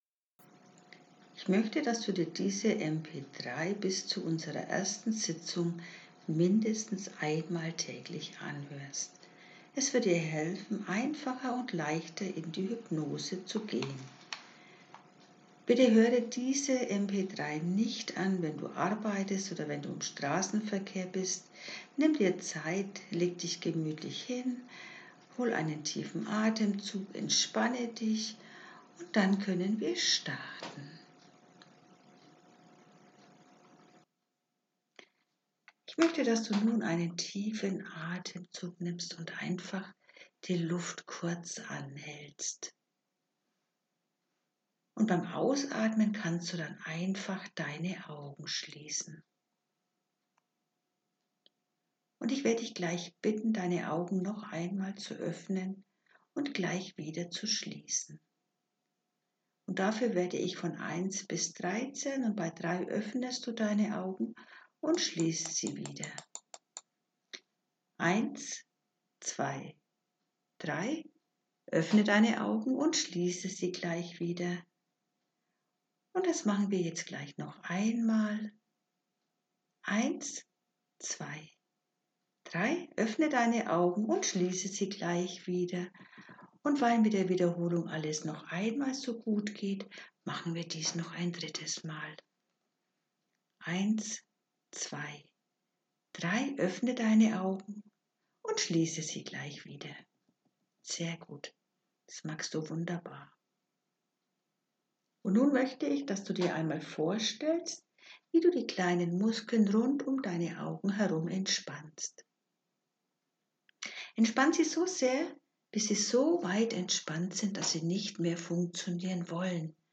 MP3 Hypnosevorbereitung
Die vorbereitende Hypnose sollten Sie mindestens 2x vor dem Hypnosetermin angehört haben, so gelangen Sie leichter in die nötige Trancetiefe und können sich schon einmal an meine Stimme gewöhnen.